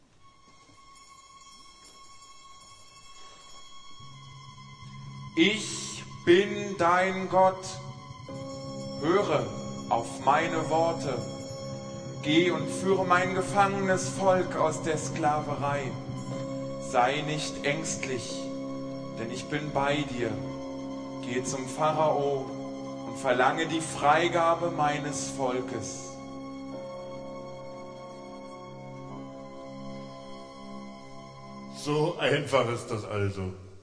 Rezitativo (Aaron)
11-rezitativo_aaron.mp3